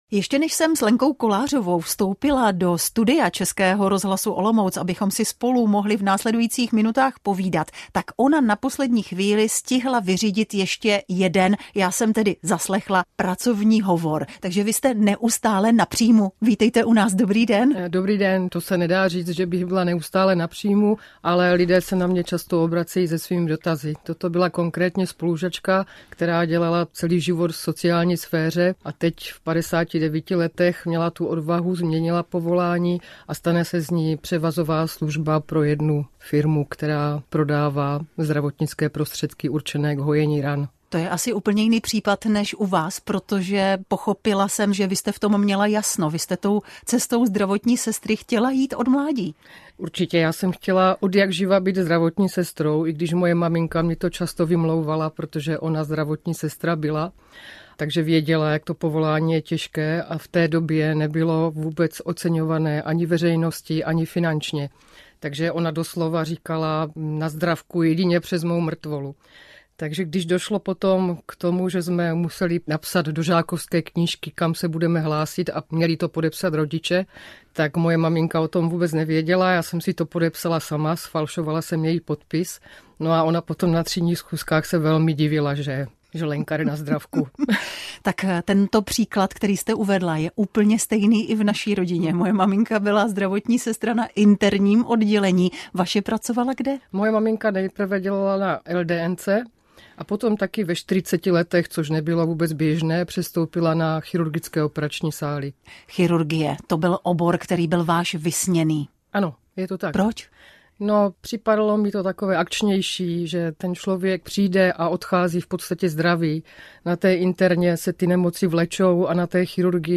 Větrník - Host ve studiu